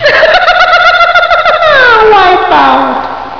Silly Voice -